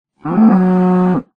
cow2.ogg